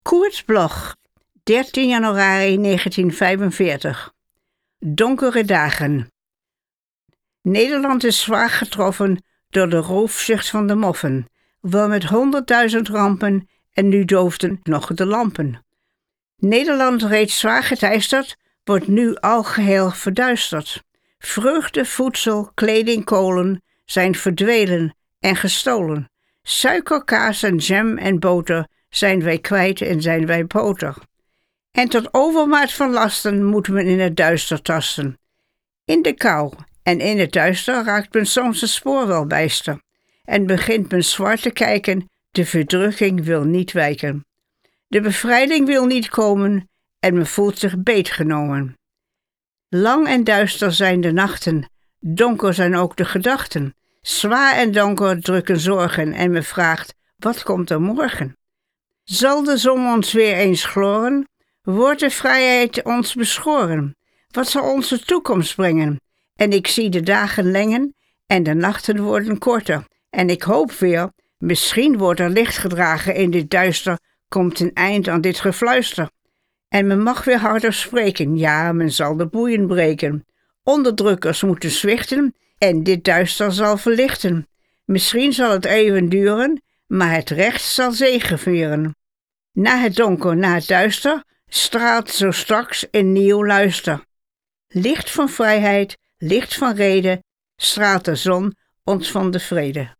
Aufnahme: Studio Levalo, Amsterdam · Bearbeitung: Kristen & Schmidt, Wiesbaden